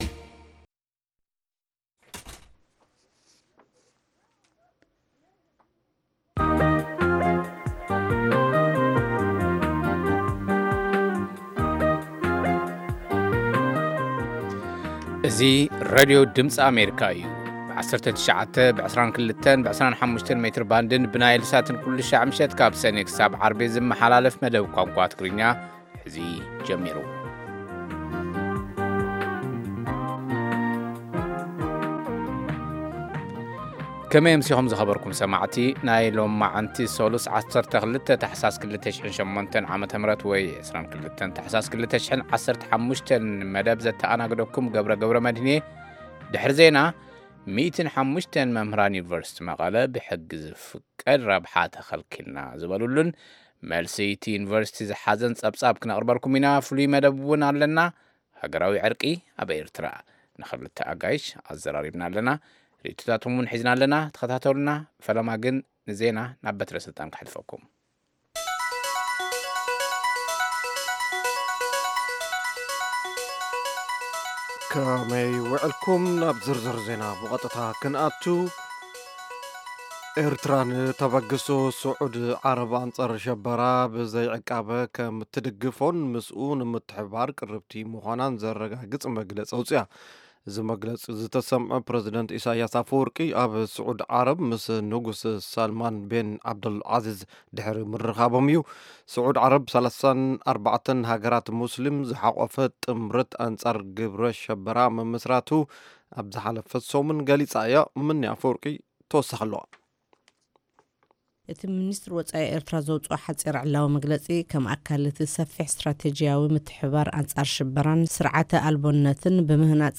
ፈነወ ትግርኛ ብናይ`ዚ መዓልቲ ዓበይቲ ዜና ይጅምር ። ካብ ኤርትራን ኢትዮጵያን ዝረኽቦም ቃለ-መጠይቓትን ሰሙናዊ መደባትን ድማ የስዕብ ። ሰሙናዊ መደባት ሰሉስ፡ ኤርትራውያን ኣብ ኣመሪካ/ ኣመሪካና